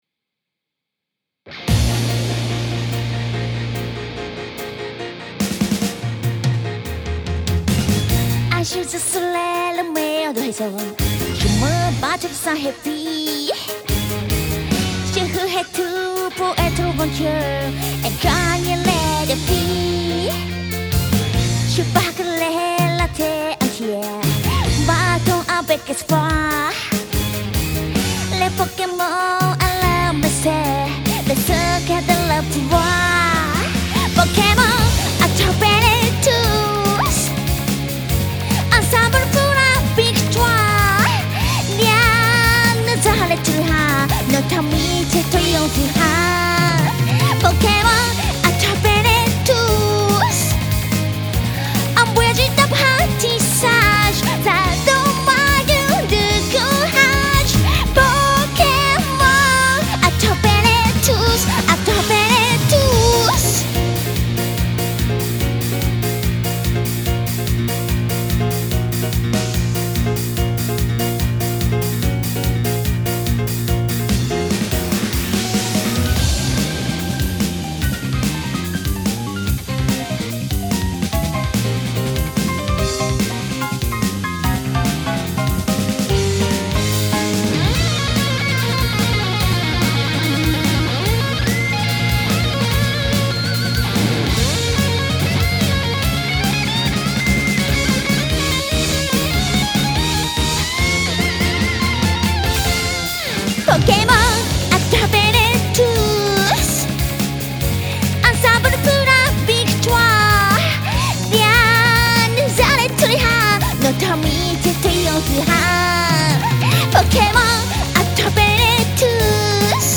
Des nippons chantent en français
la chanteuse du groupe !
le groupe japonais